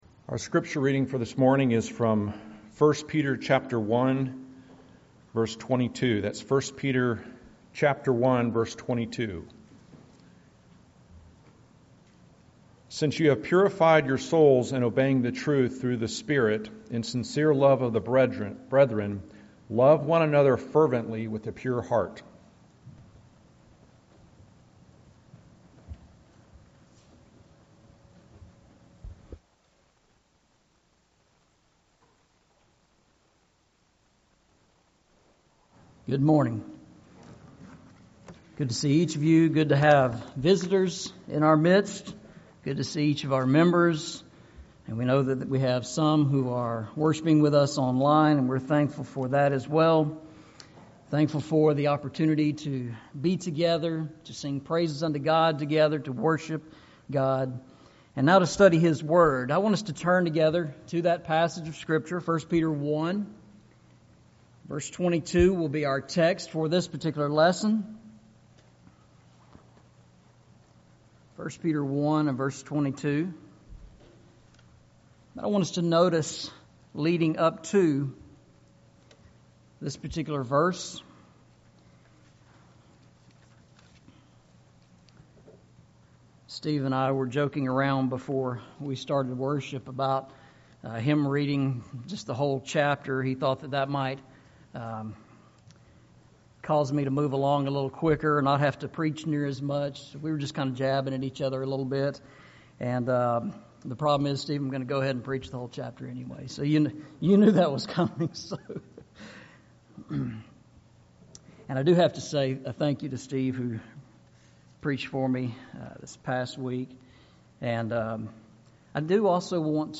Series: Eastside Sermons
Eastside Sermons Service Type: Sunday Morning Preacher